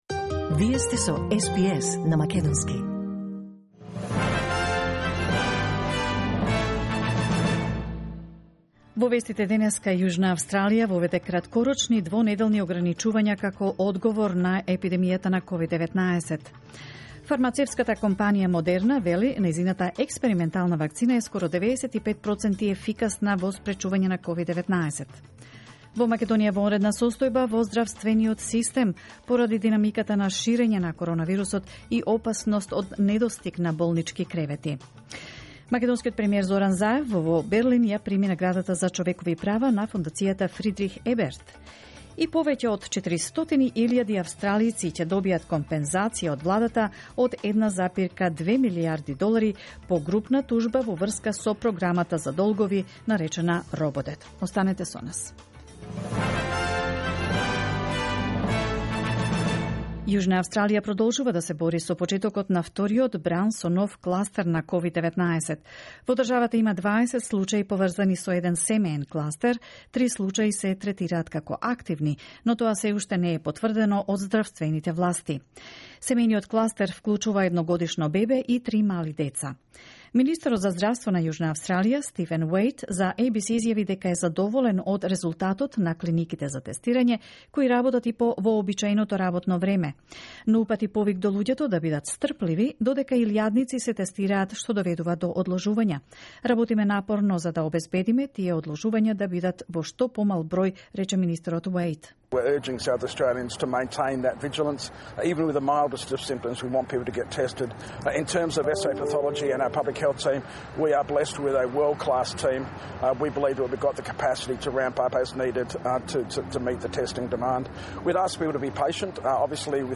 SBS News in Macedonian, 17 November 2020